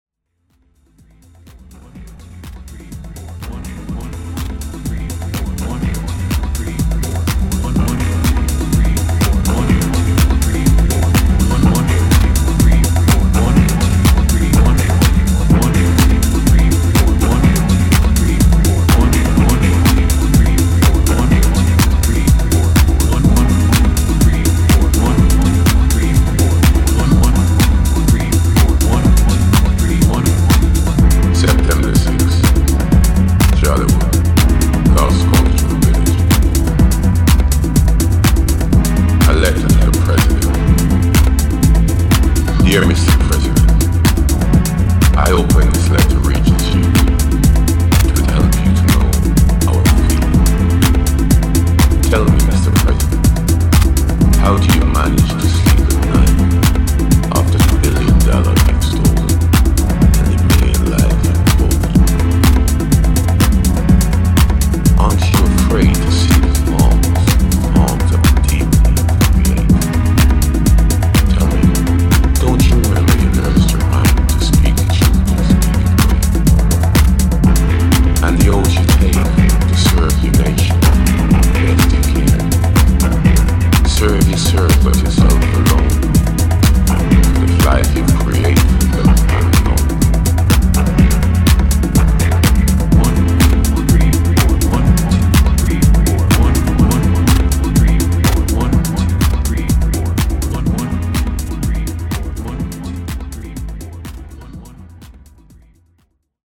UKテック・ハウスの真髄ここにあり。